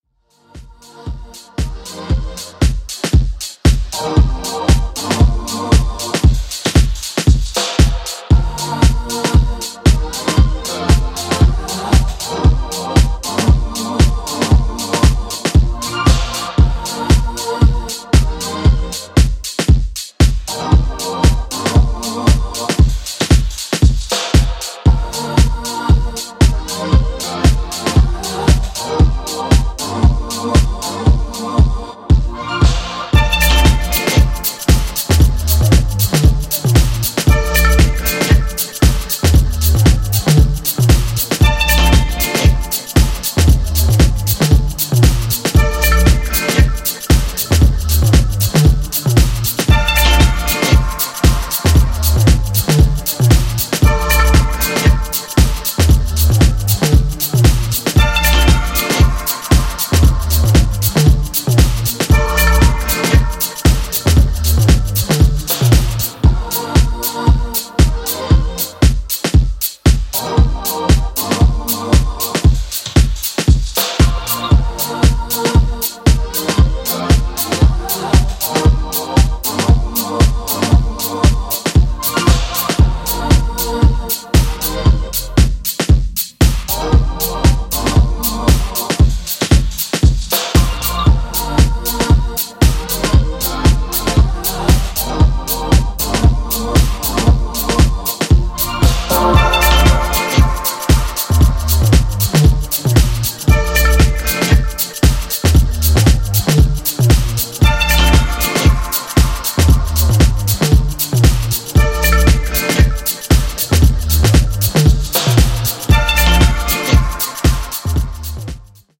keeping the energy light and summery
House